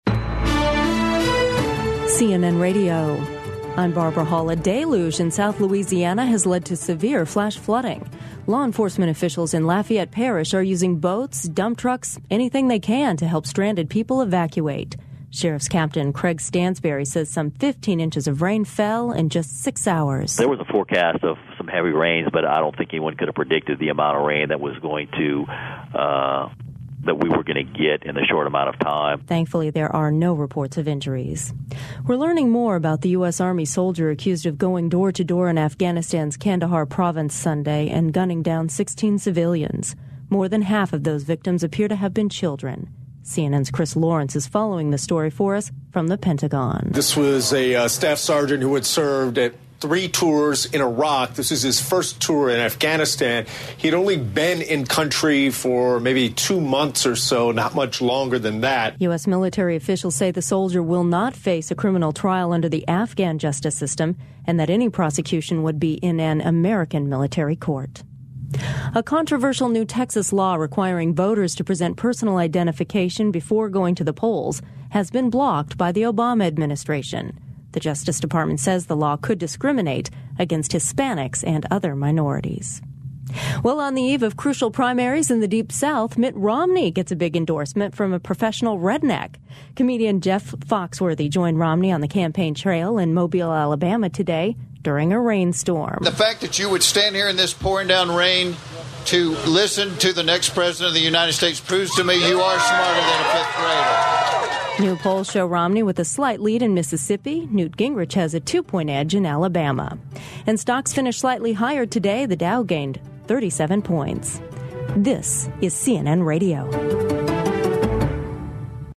CNN News,罗姆尼在密西西比州的党内预选中稍稍落后